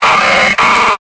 Cri de Tengalice dans Pokémon Épée et Bouclier.